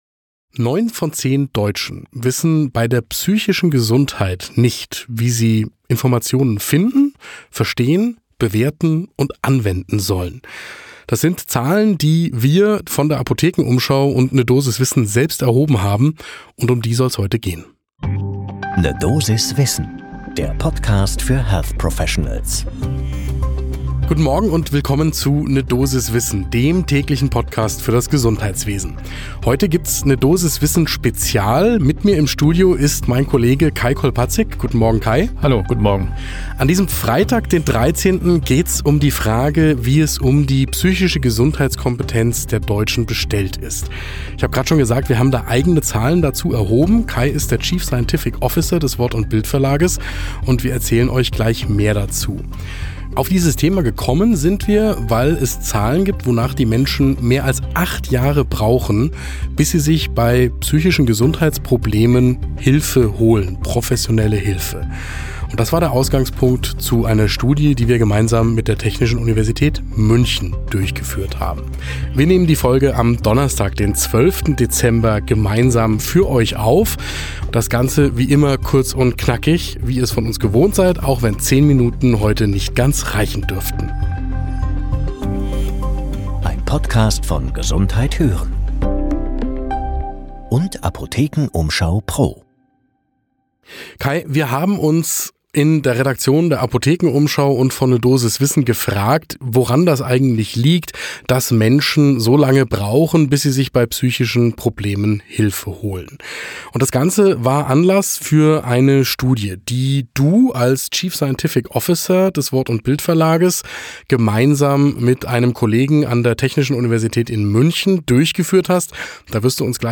"Absolut erschreckend" – Gespräch über psychische Gesundheitskompetenz in Deutschland ~ 'ne Dosis Wissen | Der Medizin-Podcast für Menschen im Gesundheitswesen Podcast